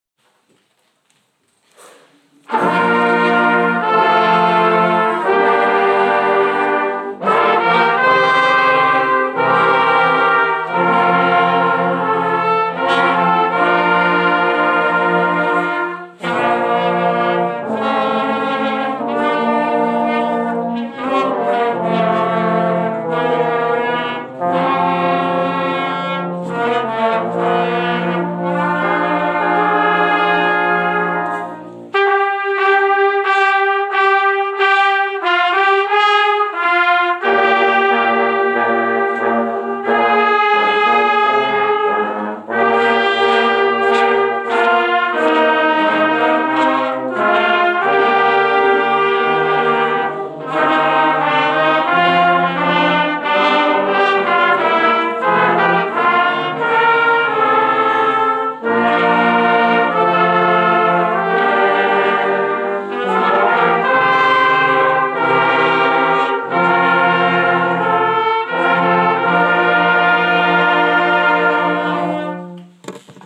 SBS Brass Band first rehearsal